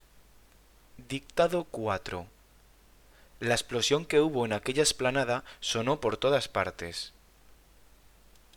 Dictado